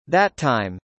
のように単語の最後のアルファベットと単語の最初のアルファベットが重なることで、本来2つ同じ単語を発生するべきところで、1つ分を省略し、前後の単語を繋げることができます。